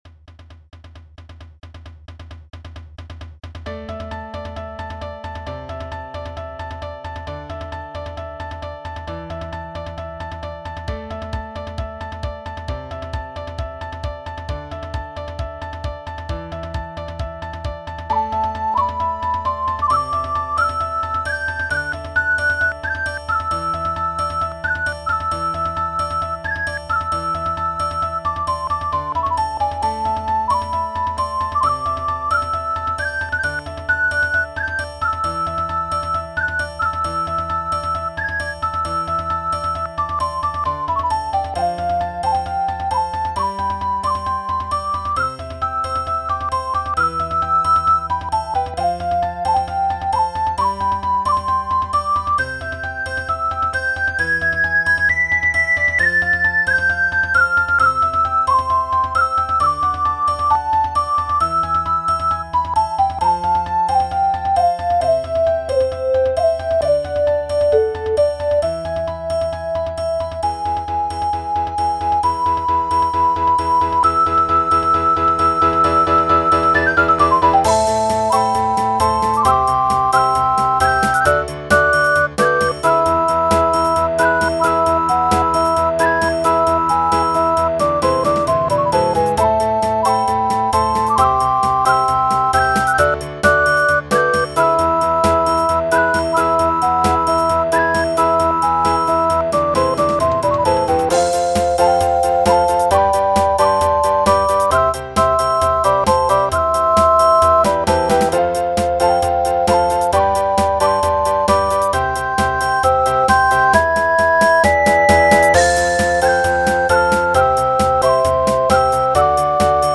５月７日　久々の笛部ステージ